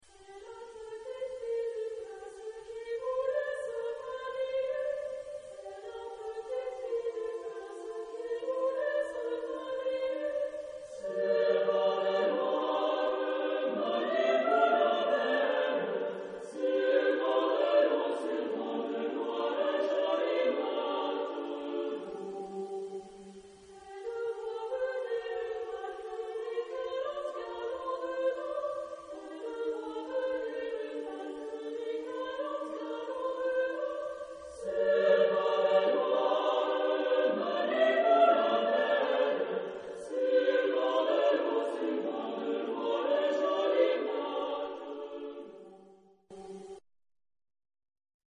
Genre-Style-Form: Secular ; Partsong ; Popular
Mood of the piece: sorrowful
Type of Choir: SATBB  (5 mixed voices )
Tonality: G minor